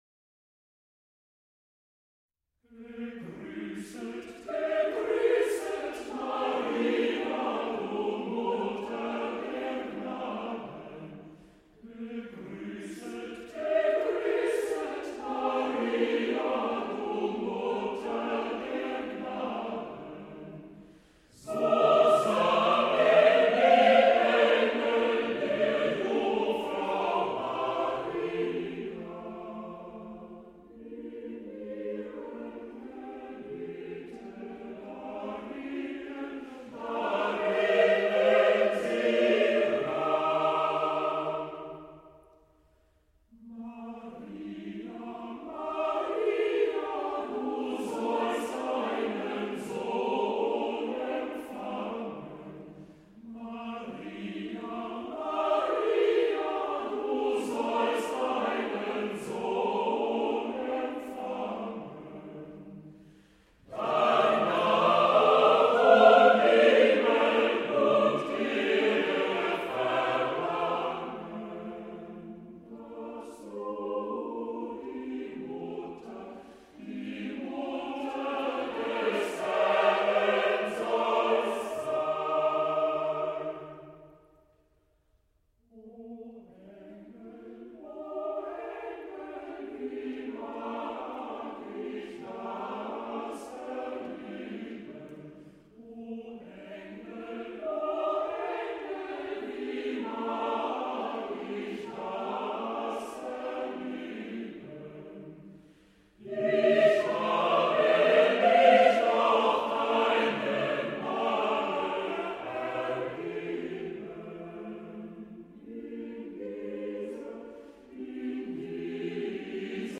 Choir
A musical ensemble of singers.
for mixed chorus a cappella